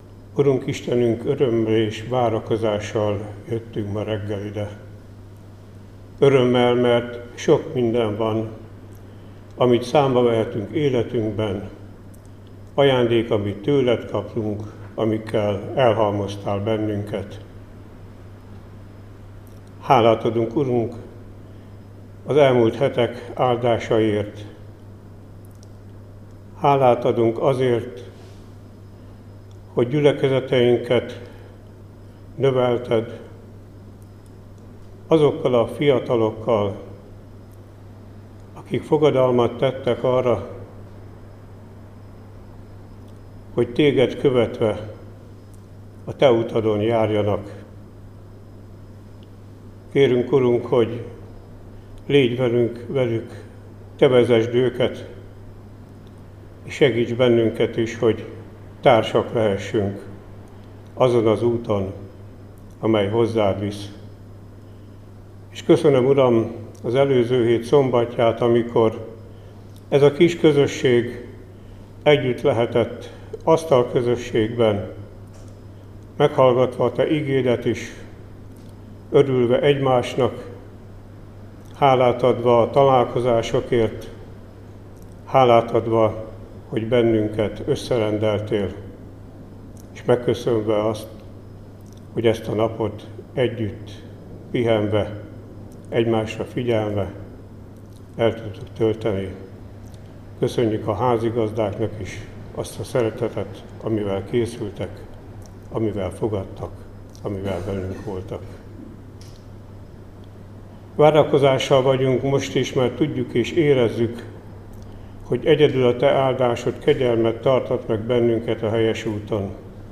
Áhítat, 2025. június 17.